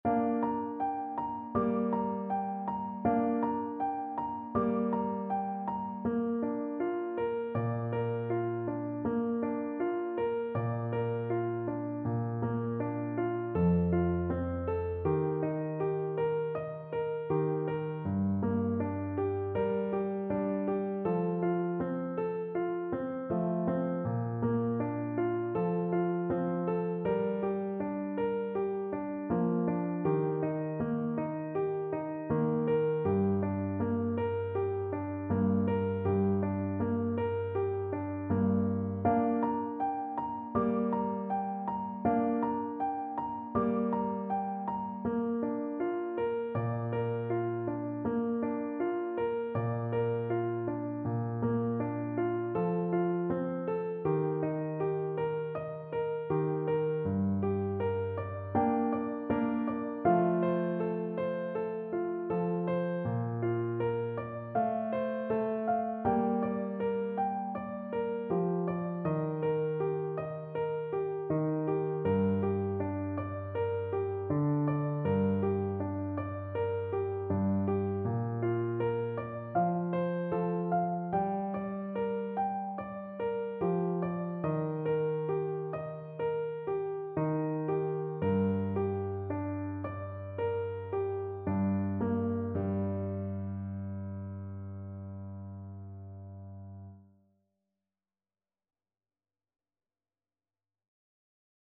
Clarinet version
Play (or use space bar on your keyboard) Pause Music Playalong - Piano Accompaniment Playalong Band Accompaniment not yet available transpose reset tempo print settings full screen
4/4 (View more 4/4 Music)
Andante
Bb major (Sounding Pitch) C major (Clarinet in Bb) (View more Bb major Music for Clarinet )
Traditional (View more Traditional Clarinet Music)